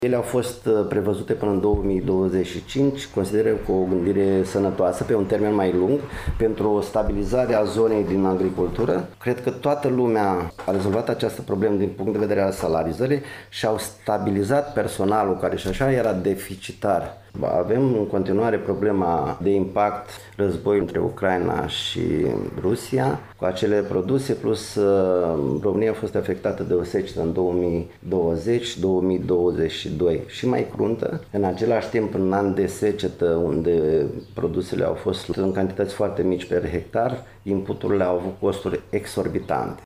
Într-o intervenție pentru Radio România Iași